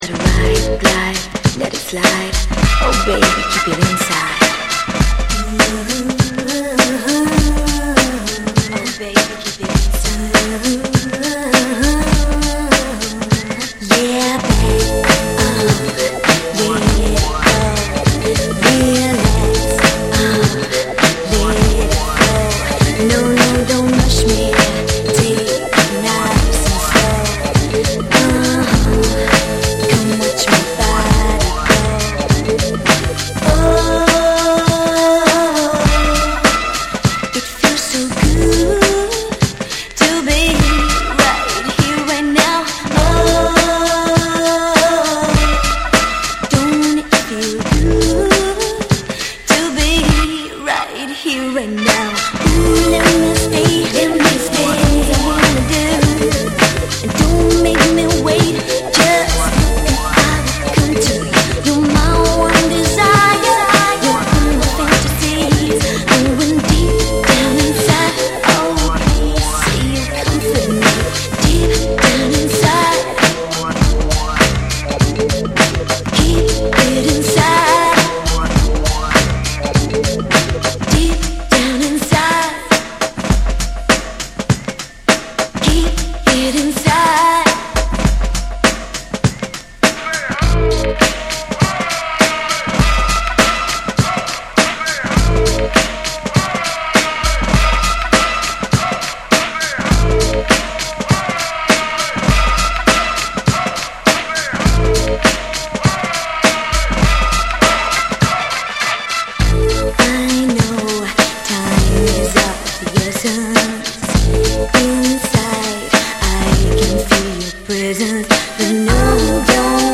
エレクトロ、ファンク、ブレイクビーツがスモーキーに溶け合うタイトル曲
BREAKBEATS